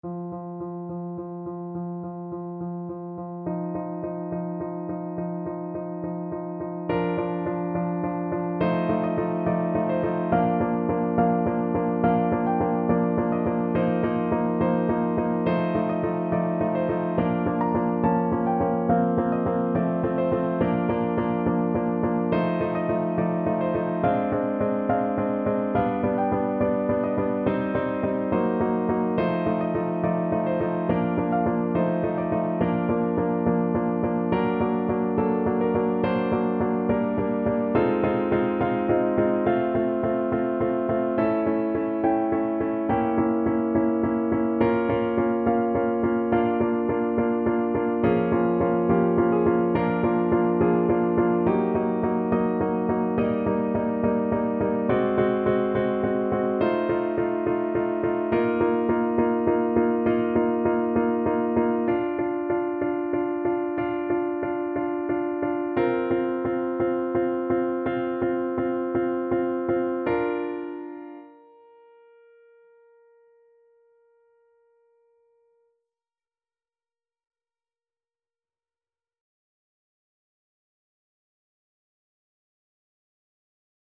Classical Saint-Saëns, Camille Tortues (Tortoises aka Can-Can) from Carnival of the Animals Piano version
No parts available for this pieces as it is for solo piano.
4/4 (View more 4/4 Music)
Bb major (Sounding Pitch) (View more Bb major Music for Piano )
Andante maestoso
Piano  (View more Intermediate Piano Music)
Classical (View more Classical Piano Music)
saint_saens_the_tortoise_PNO.mp3